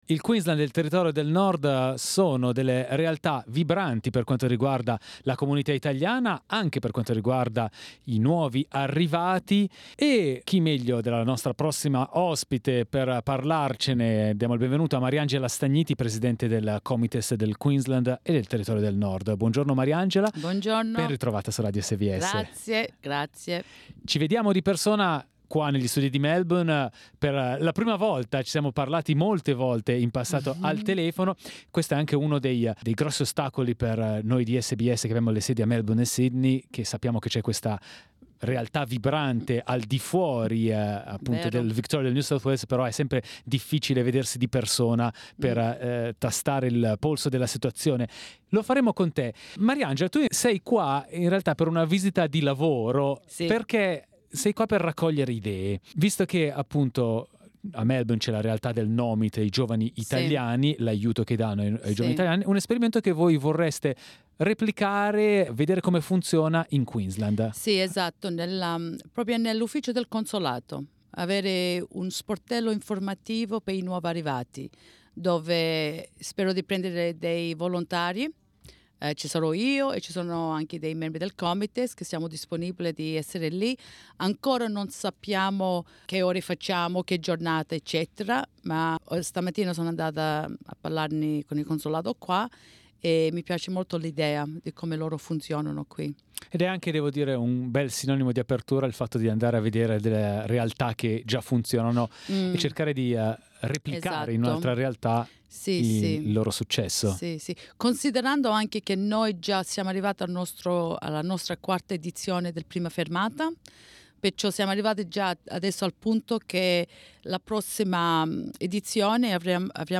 in our studios